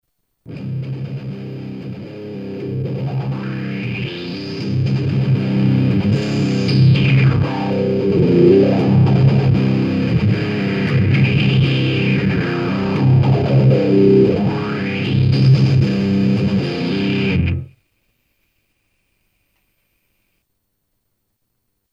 Druhá vyzerá na nejaký typ flangeru.
+ som sa pohral so stereom. ale potrebujem to dostat do skatulky na zem a ovladat to slapkou.Vravia ze aj urcity druh wah pedalu by to zvladol takto nejako..
Inak sa to da dosiahnut aj Noise Gate-om, ale ""umelym zvukarskym zasahom"" to bude zniet asi presvedcivejsie. 2.ukazka sa bezne robi flangerom alebo phaserom.